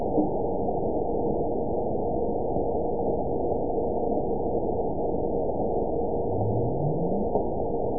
event 920122 date 02/24/24 time 02:34:47 GMT (1 year, 2 months ago) score 9.76 location TSS-AB02 detected by nrw target species NRW annotations +NRW Spectrogram: Frequency (kHz) vs. Time (s) audio not available .wav